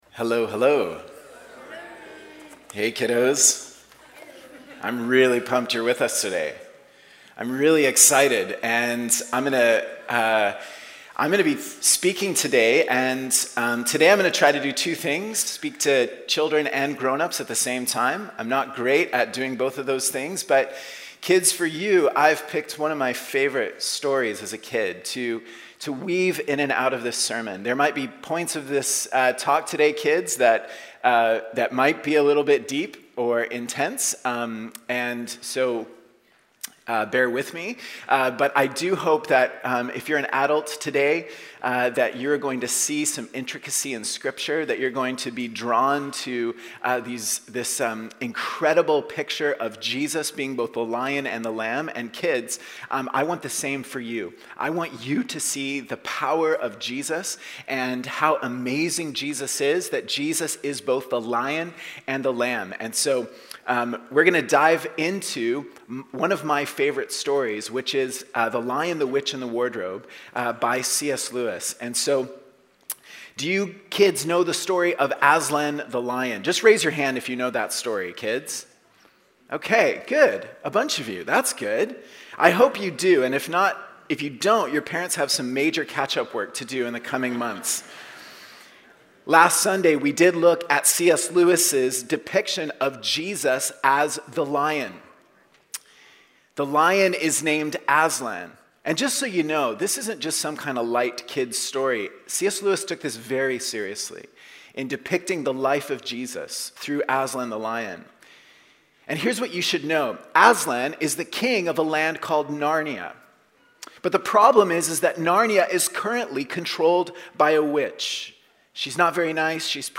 Walnut Grove Sermons | North Langley Community Church
Baptism Sunday